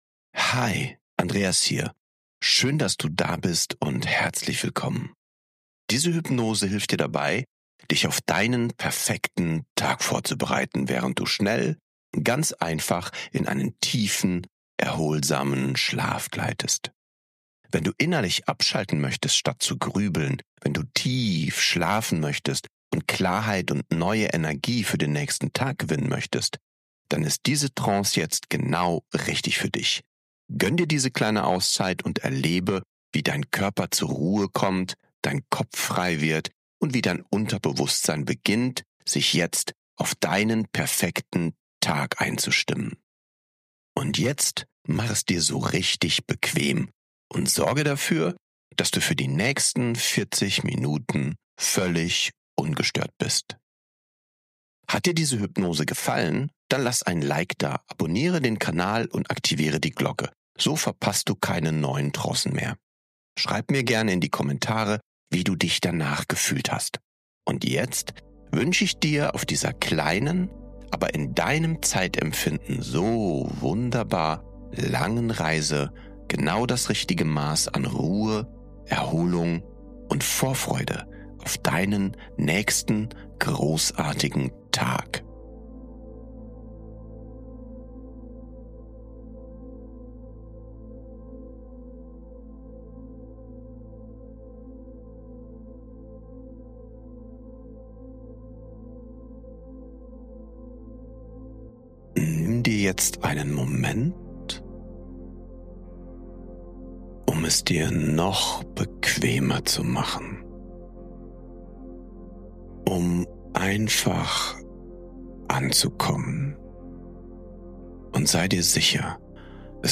Diese geführte Abend-Hypnose bringt dich schnell in einen tiefen, erholsamen Schlaf und bereitet dein Unterbewusstsein gleichzeitig optimal auf deinen nächsten Tag vor. Mit bewährten NLP- & Mentaltechniken programmierst Du Dein Unterbewusstsein auf Klarheit, Fokus & Spitzenleistung.